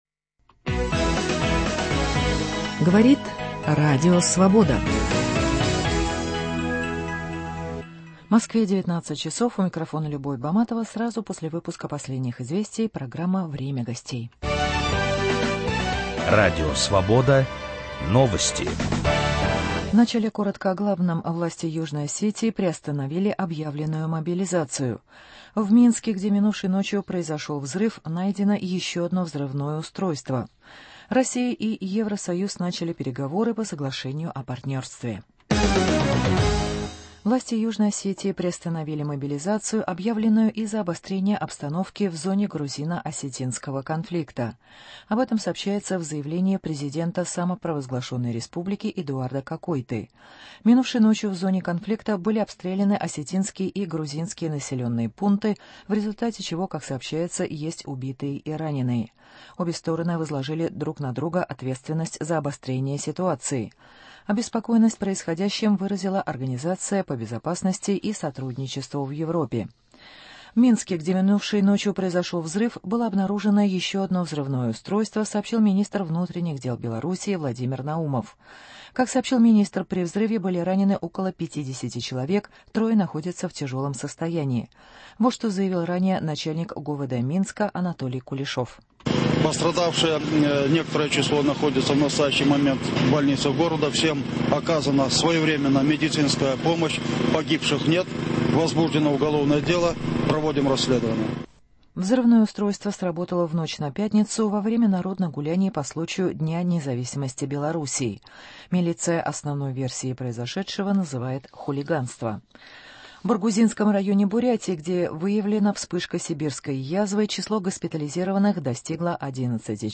О российско-украинских экономических взаимоотношениях с ведущим Виталием Портниковым беседует бывший премьер Украины и депутат Верховной Рады Анатолий Кинах.